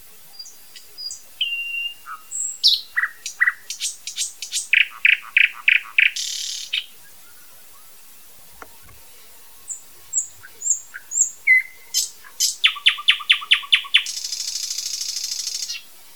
А мне с моим соловьём как-то и неудобно вдруг стало, но всё же покажу.